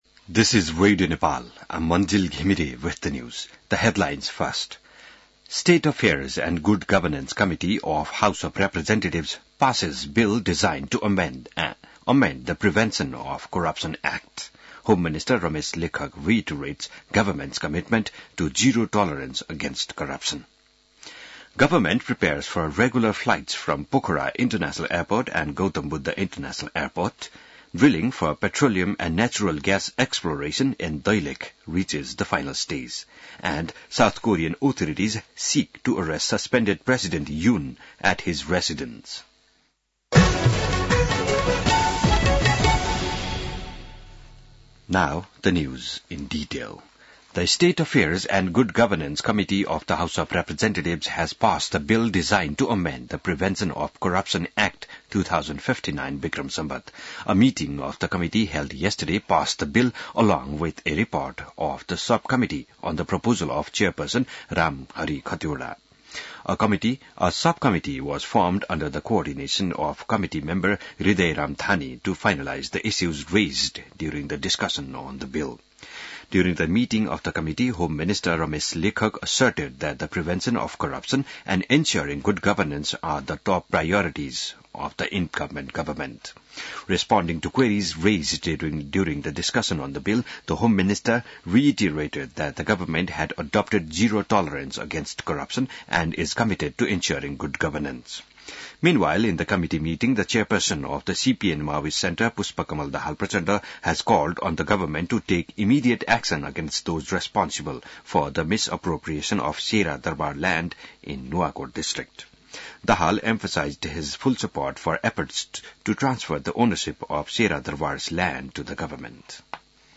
बिहान ८ बजेको अङ्ग्रेजी समाचार : २० पुष , २०८१